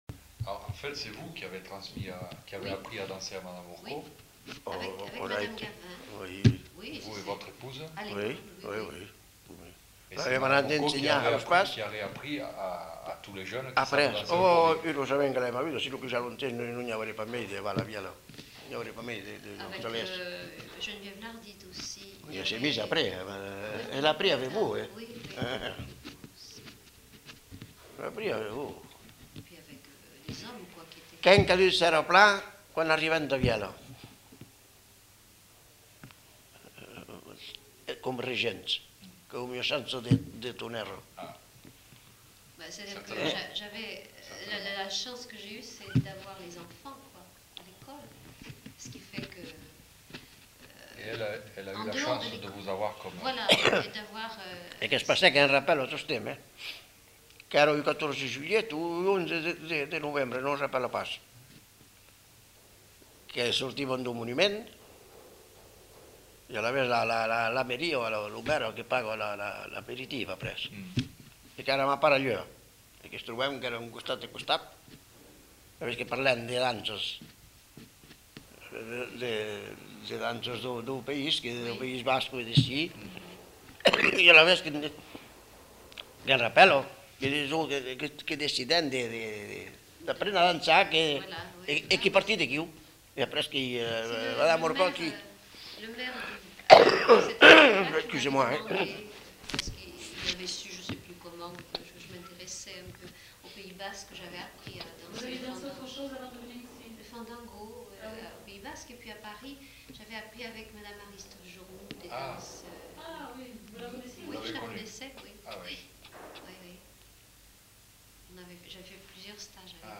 Lieu : Bielle
Genre : témoignage thématique